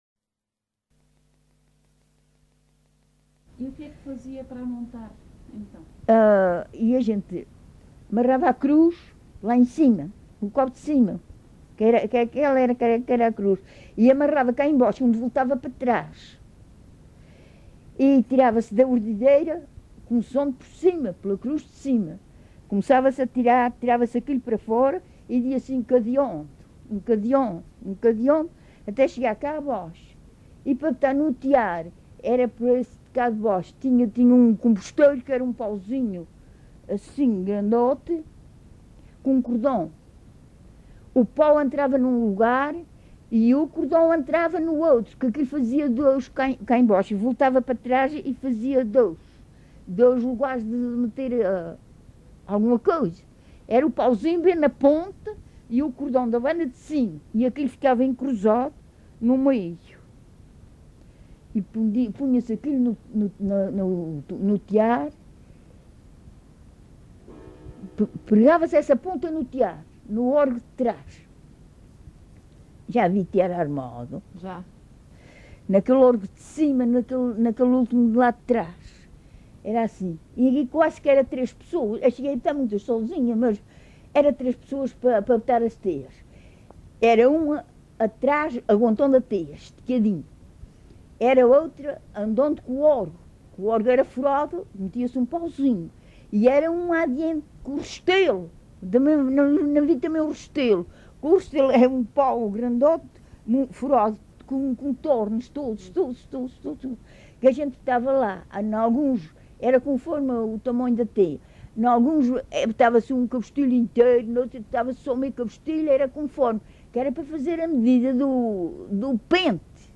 LocalidadeCosta do Lajedo (Lajes das Flores, Horta)